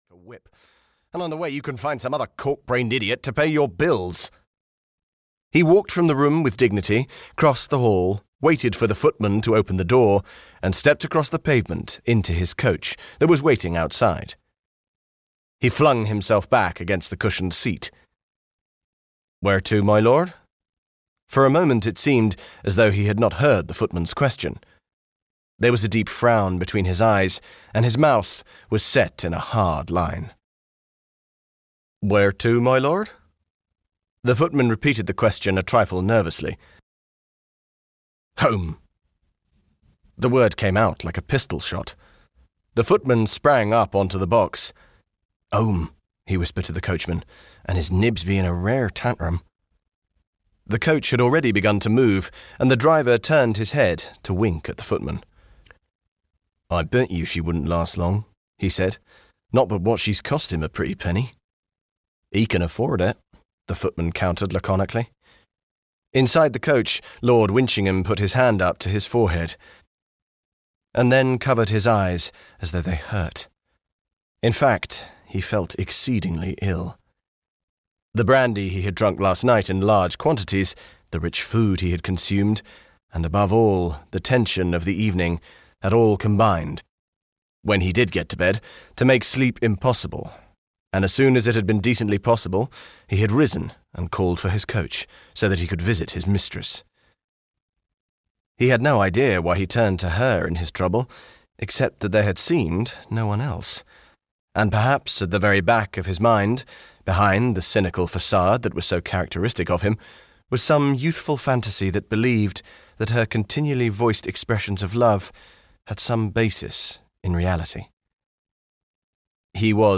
Audiobook Love Holds the Cards, which was written by Barbara Cartland.
Audio knihaLove Holds the Cards (EN)
Ukázka z knihy